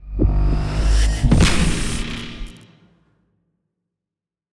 eldritch-blast-001.ogg